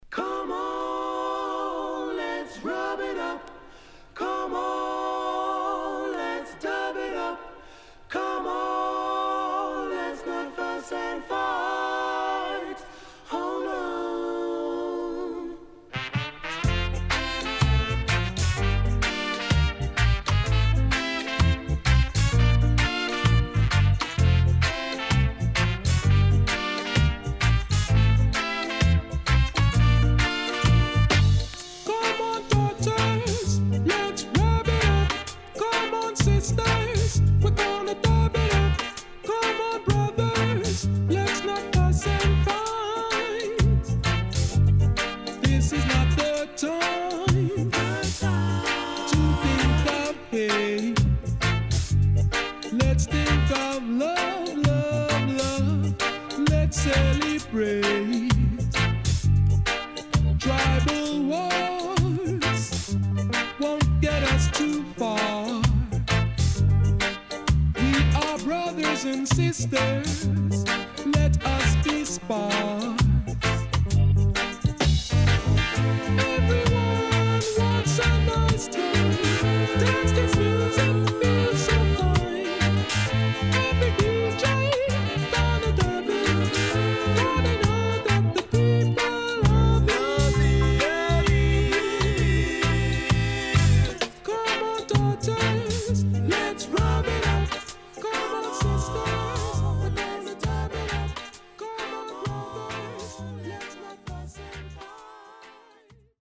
SIDE A:少しチリノイズ入ります。